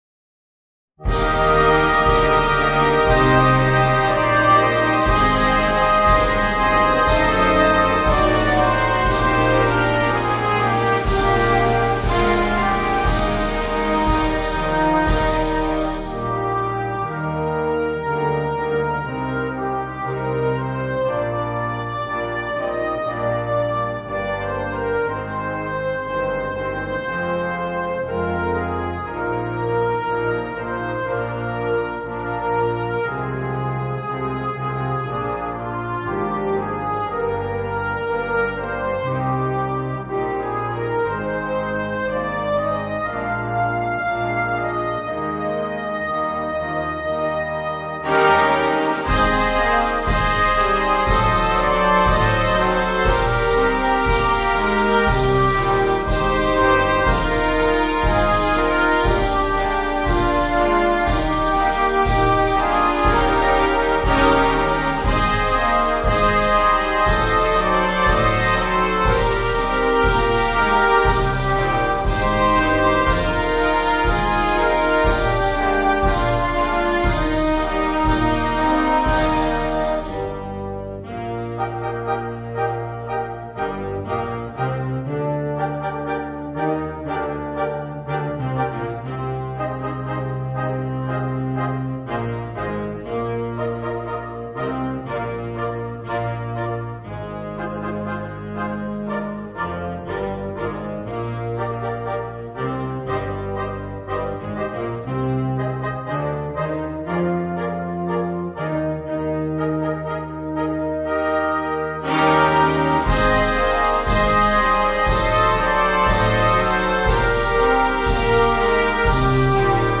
Inno religioso